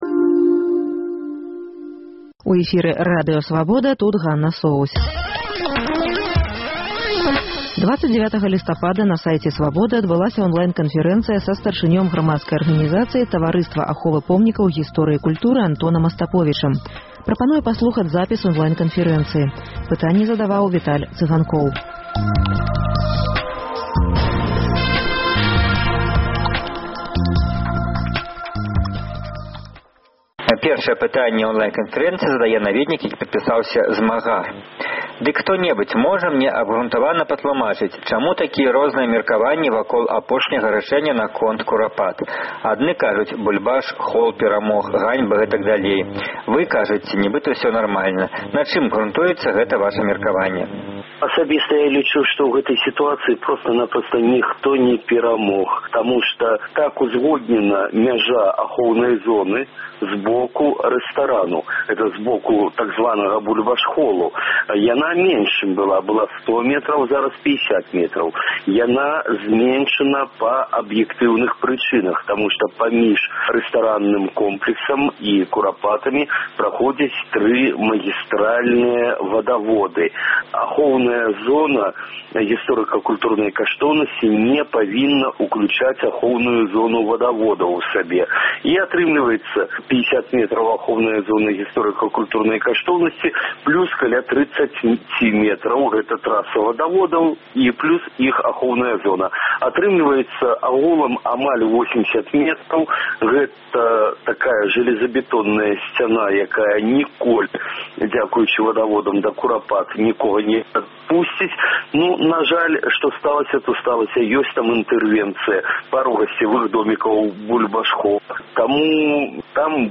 Радыёварыянт онлайн-канфэрэнцыі